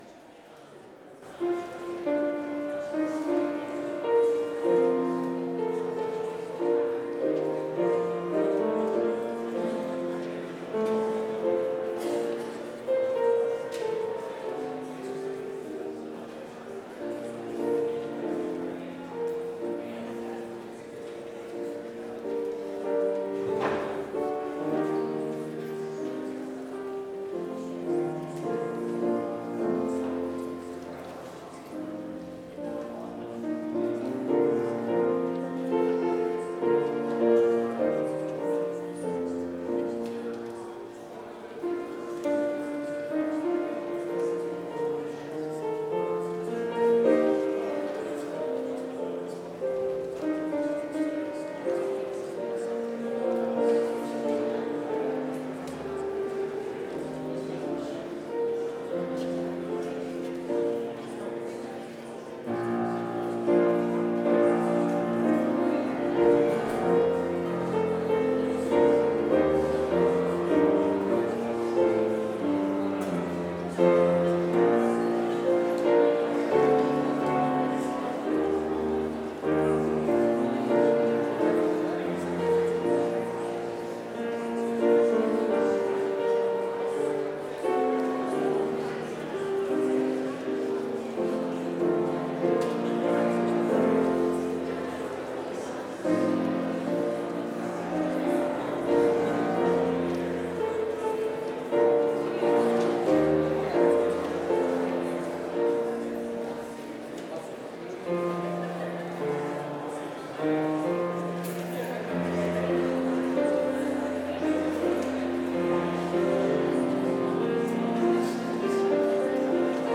Complete service audio for Chapel - Thursday, November 6, 2025